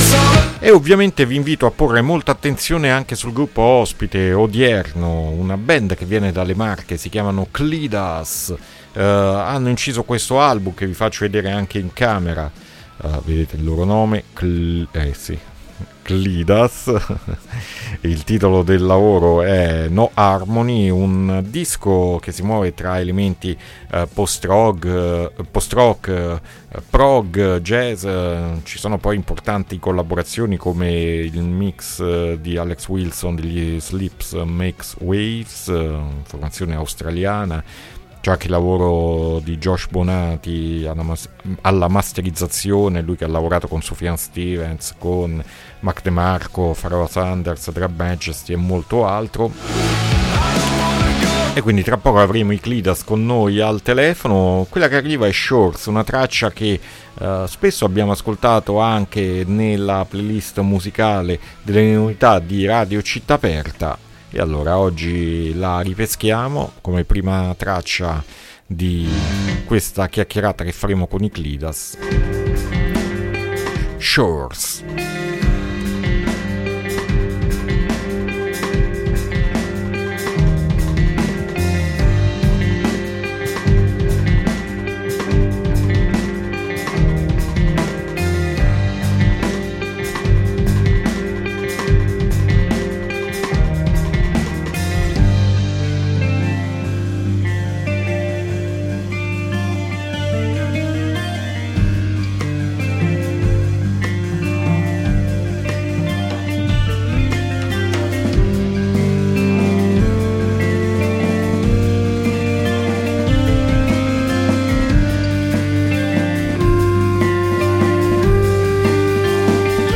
INTERVISTA KLIDAS A PUZZLE 3-7-2023